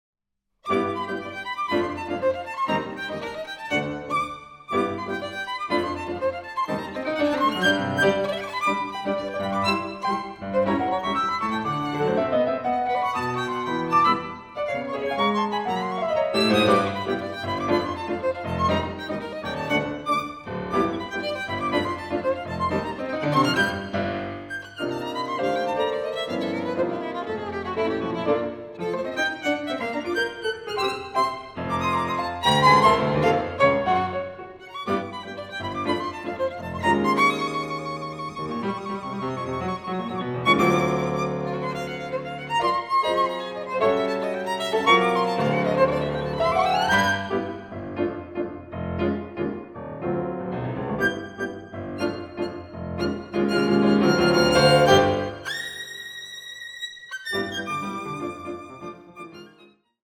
Lento moderato